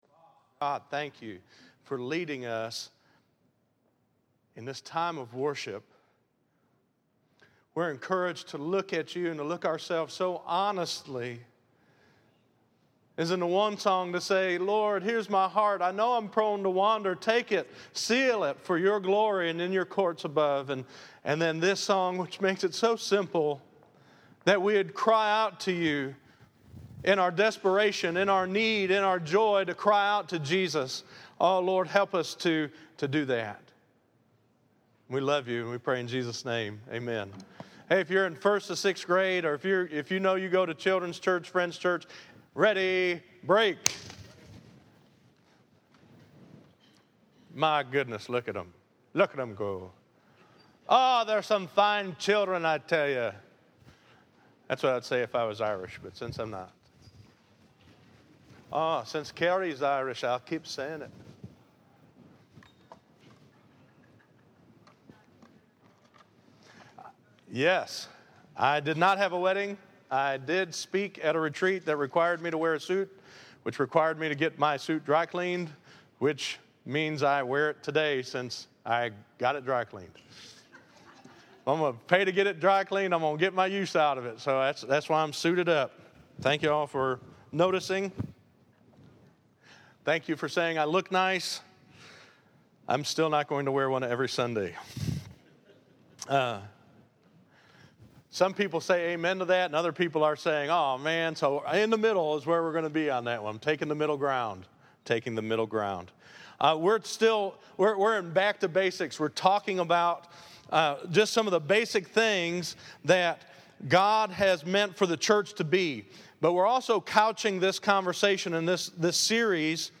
Listen to Preaching the Word and Praying the Word of Faith - 09_14_14_Sermon.mp3